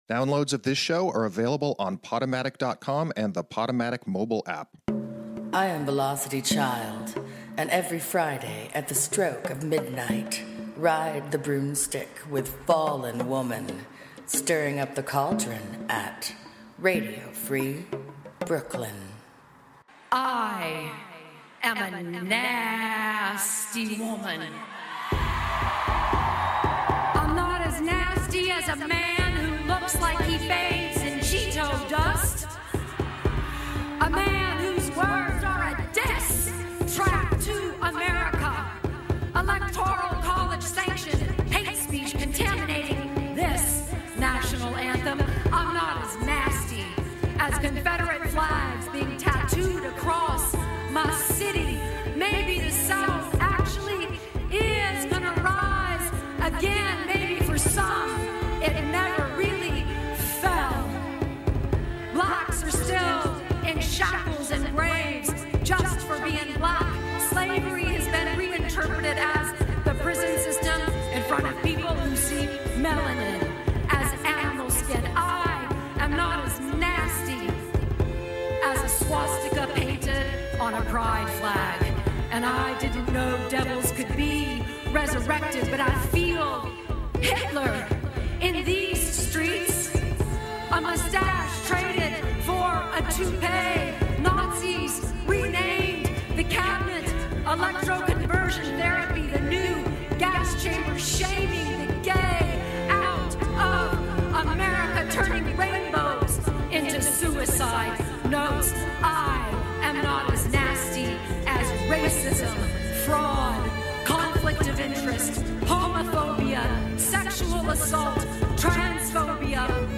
Headliner Embed Embed code See more options Share Facebook X Subscribe Featuring a special musical edition with the intention of providing a background to eclipsing the patriarchy with divine feminism & or binding Kavanaugh with your spell & our suggestive song.